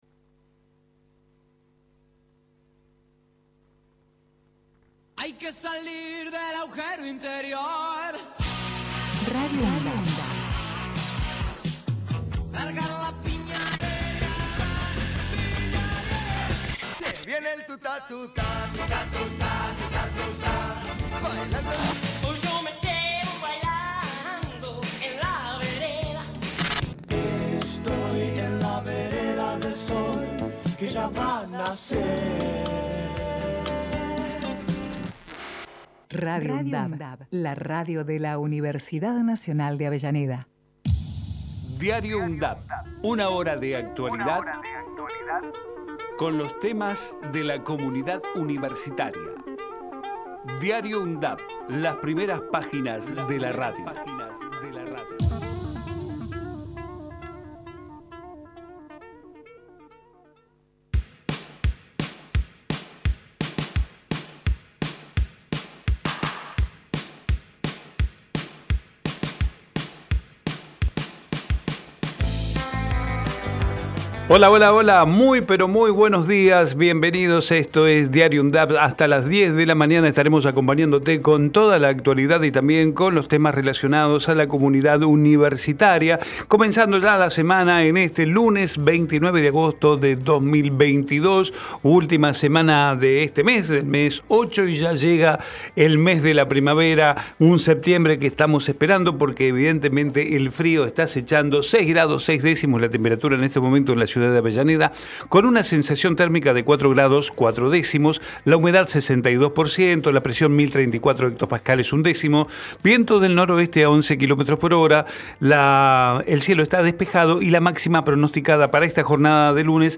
Diario UNDAV Texto de la nota: De lunes a viernes de 9 a 10 realizamos un repaso por la actualidad universitaria en las voces de los protagonistas, testimonios de quienes forman parte de la UNDAV. Investigamos la historia de las Universidades Nacionales de todo el país y compartimos entrevistas realizadas a referentes sociales, culturales y académicos. Todos los días, cerramos Diario UNDAV, con nuestro ciclo de efemérides musicales, histórico y variado.